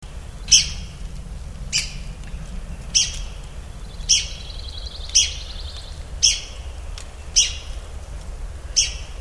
Red-cockaded Woodpecker Call
The call of the red-cockaded woodpecker (Picoides borealis).
RCW_calls.mp3